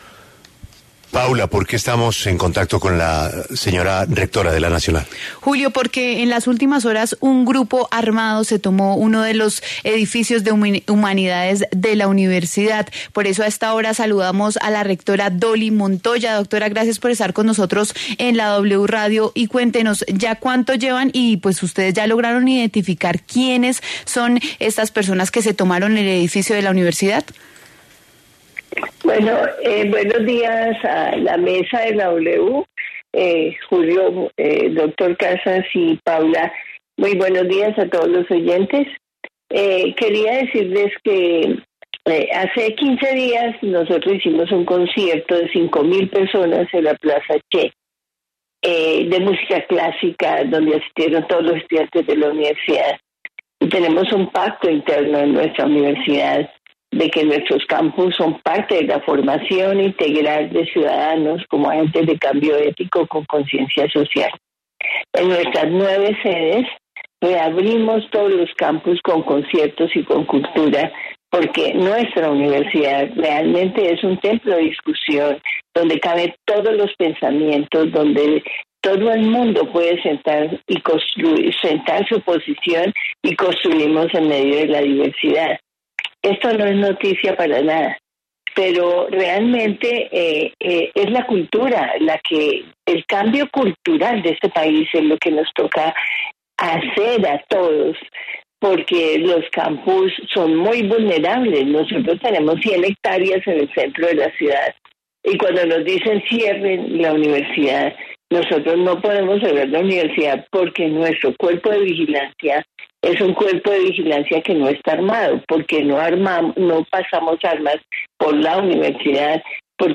En diálogo con La W, la rectora Dolly Montoya se pronunció sobre estos hechos y subrayó que la institución también es víctima del conflicto.